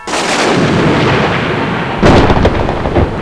DONNER.WAV